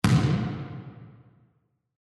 Звук мяча ударившегося о пол спортзала